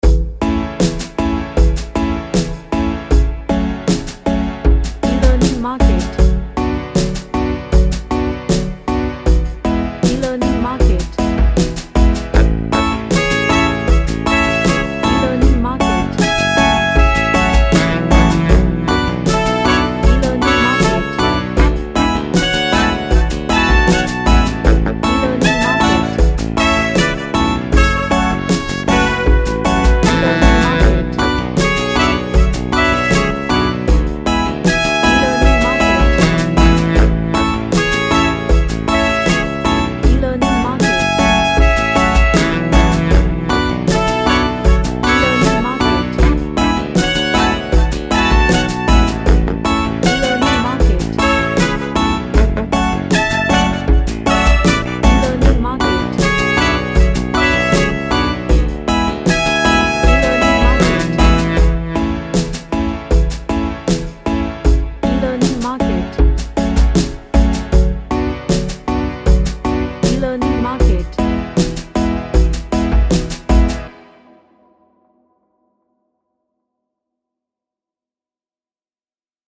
A Funky Vibed song with melody of session horns.
Happy / CheerfulFunky